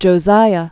(jō-sīə, -zīə) Died c. 609 BC.